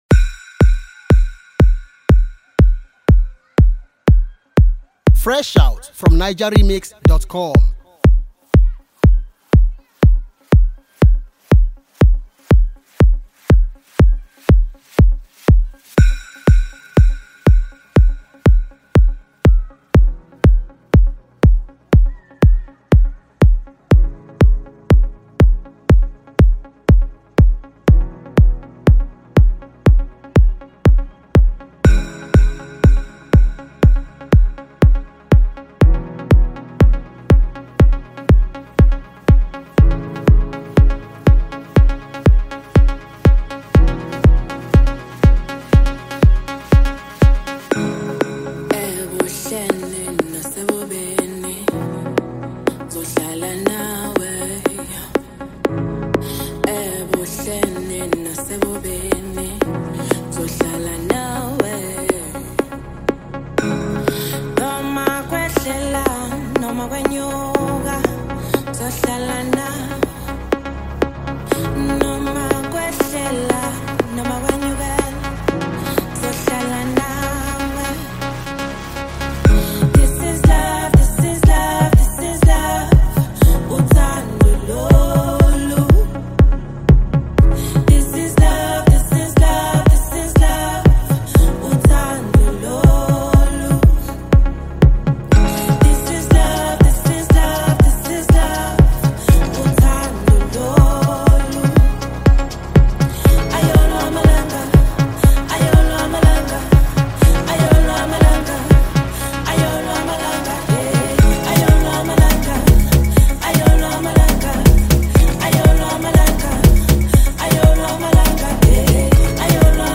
a powerhouse vocalist